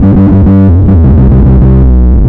FINGERBSS6-L.wav